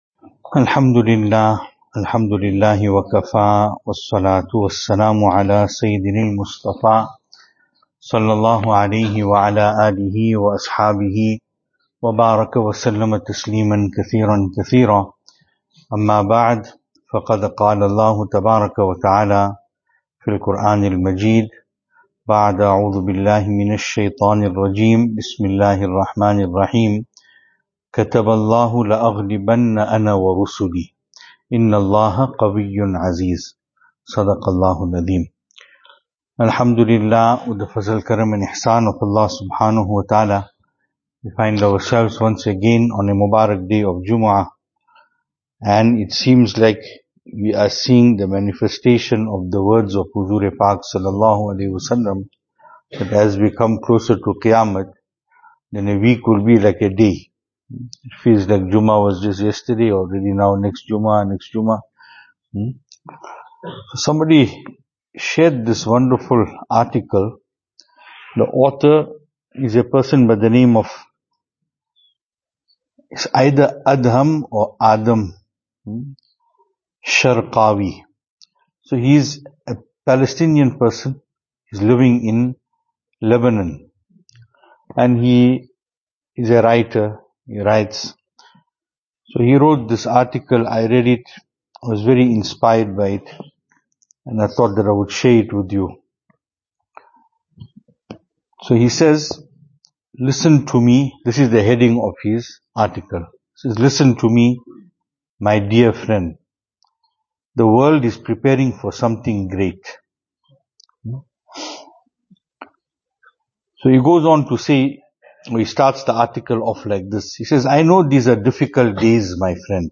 Venue: Albert Falls , Madressa Isha'atul Haq Series: JUMUAH Service Type: Jumu'ah Topics: JUMUAH « Advices for those leaving for Umrah, Part 1.